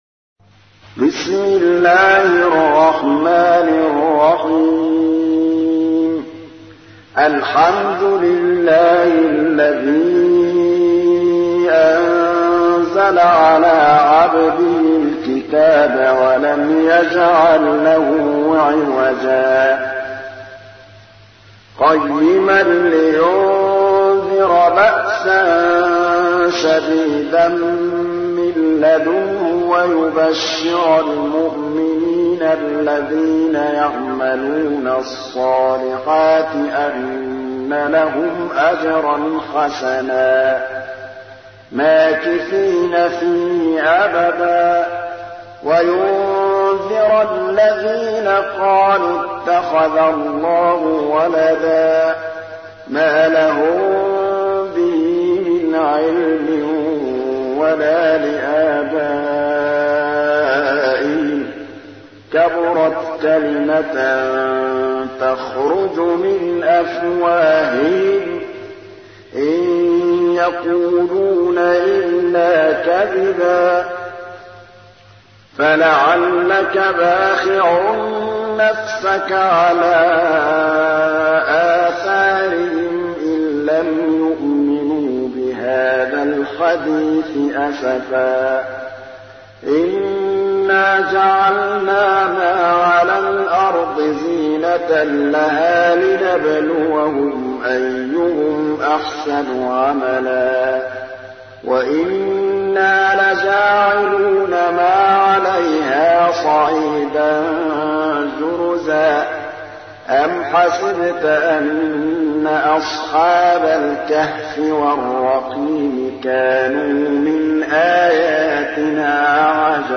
تحميل : 18. سورة الكهف / القارئ محمود الطبلاوي / القرآن الكريم / موقع يا حسين